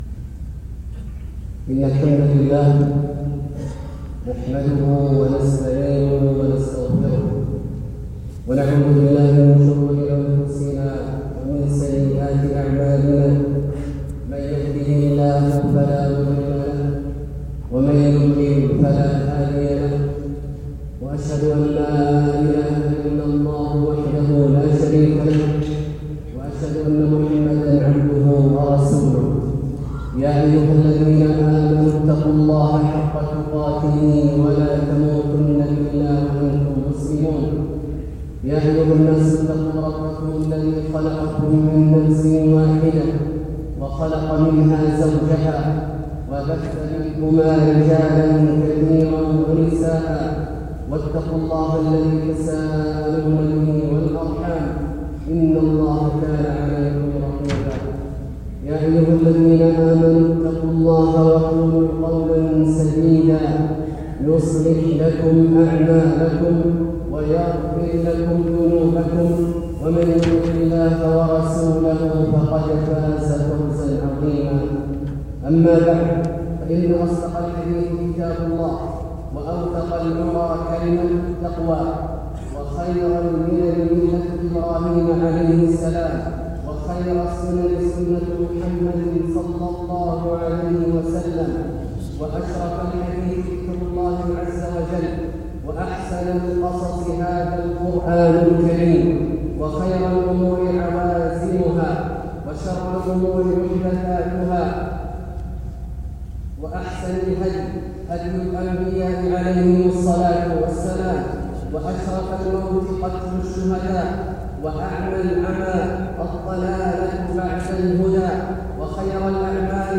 خطبة الجمعة للشيخ عبدالله الجهني في جامع الملك عبدالله بمدينة الملك فيصل العسكرية | 23 محرم 1447هـ > زيارة فضيلة الشيخ أ.د. عبدالله الجهني للمنطقة الجنوبية | محرم 1447هـ > المزيد - تلاوات عبدالله الجهني